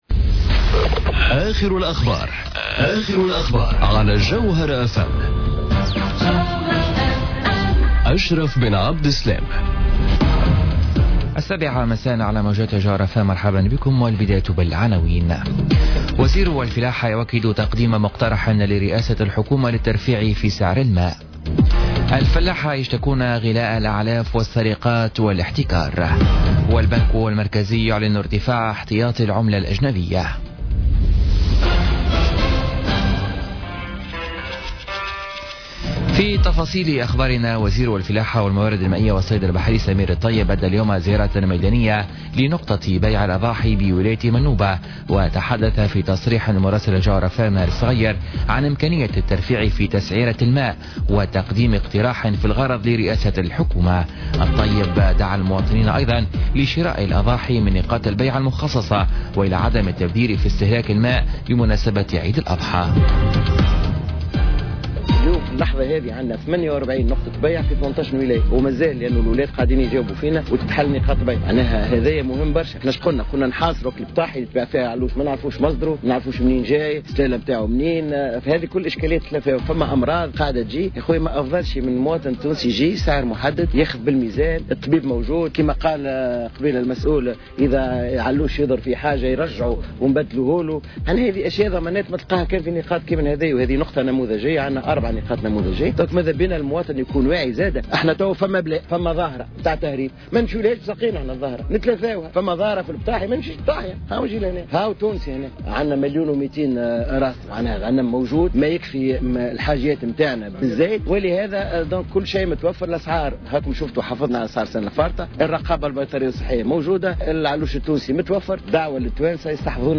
نشرة أخبار السابعة مساء ليوم الخميس 24 أوت 2017